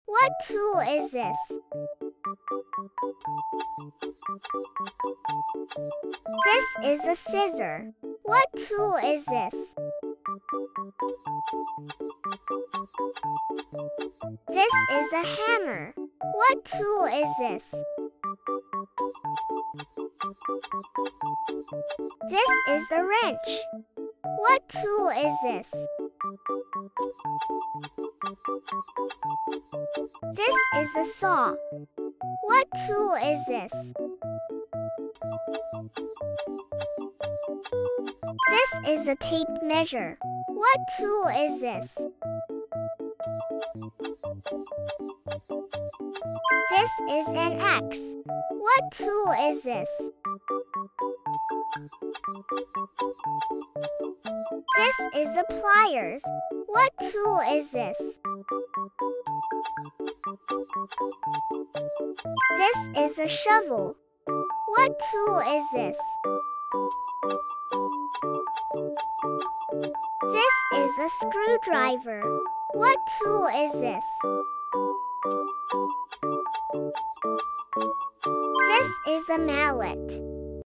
Can You Guess The Tools Sound Effects Free Sound Effects You Can Also Send Email This Can You Guess Sound Effect Or Share With Friends With Just Click On Share Button